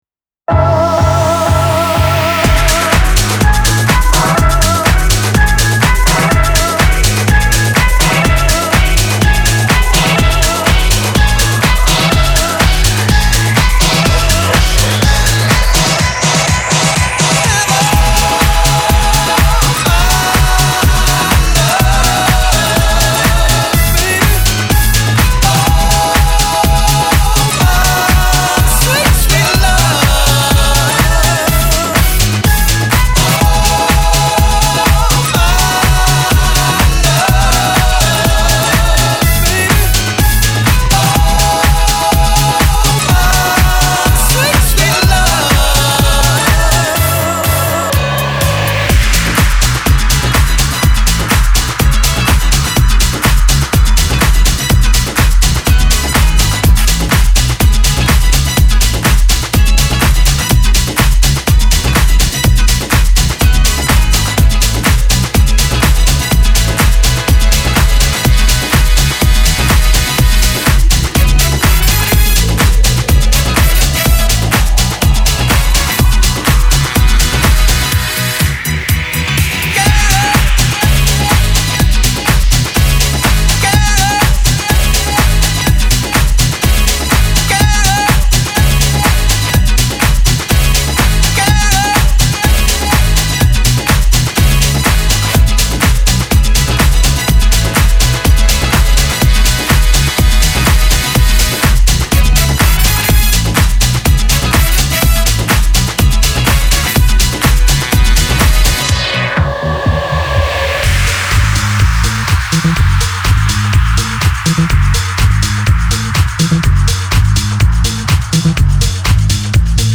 Jackin House Radio